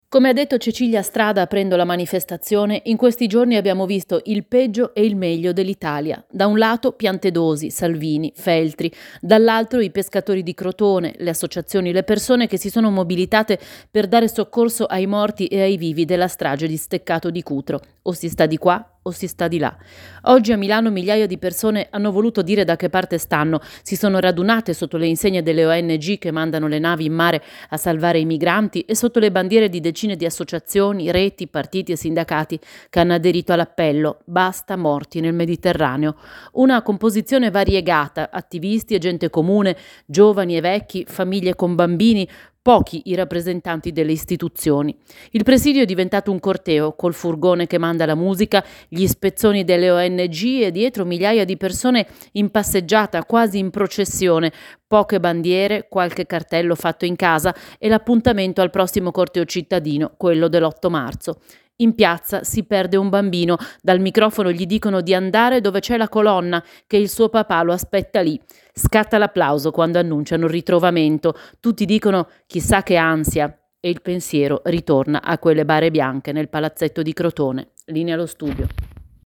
Il presidio è diventato un corteo, col furgone che manda la musica, gli spezzoni delle Ong e dietro migliaia di persone in passeggiata, quasi in processione, poche bandiere, qualche cartello fatto in casa e l’appuntamento al prossimo corteo cittadino, quello dell’ 8 marzo.